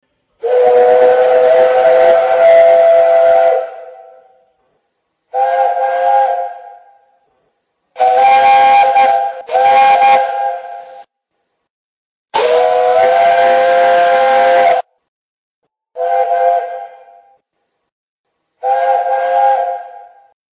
Recorded Whistles for Live Steam Locomotives
Gresley Chime
whistles_gresley_chime.mp3